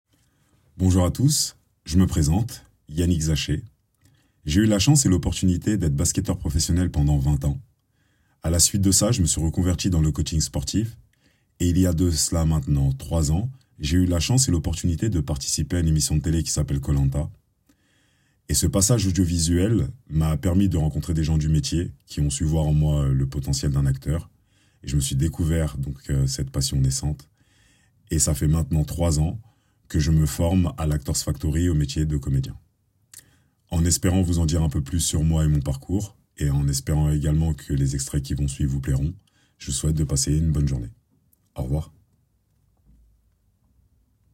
30 - 55 ans - Basse Baryton-basse